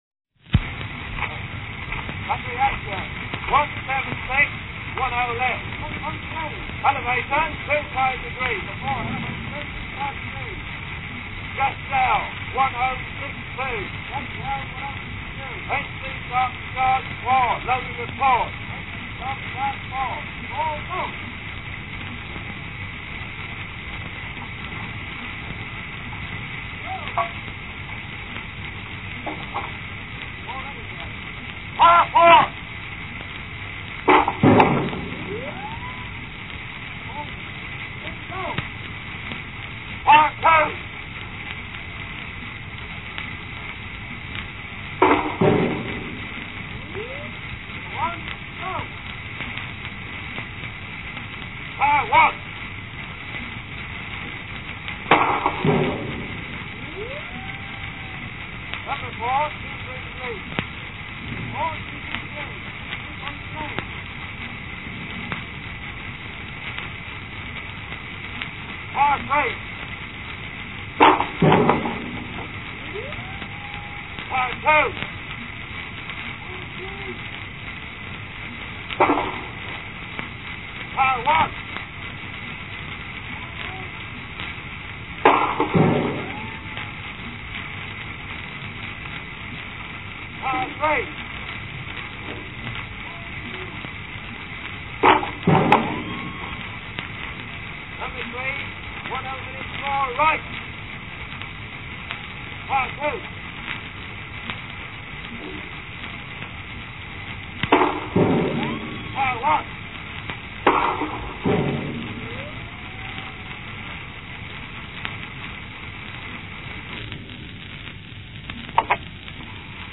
Nonetheless the “Gas Shell Bombardment” record – a 12-inch HMV shellac disc, just over 2 minutes at 78 rpm – was released a few weeks later, just as the war came to an end.
This “historic recording,” says the subtitle, is an “actual record taken on the front line.”
In particular, he singled out one indistinct rattly flap-whizz noise, hearing in it, he claimed, the sound of a round with a “loose driving-band.”
He heard in it, he said, an unmistakable succession of sounds – the clang of the breech, the gigantic report of the firing explosion, the distinctive whiny whistle of a gas shell on its way across no-man’s-land.
He also identified the audible echo effect – the curious “double report” of the guns heard here – as the sound of a brass recording horn violently resonating at a distance of exactly 26.5 meters from the guns.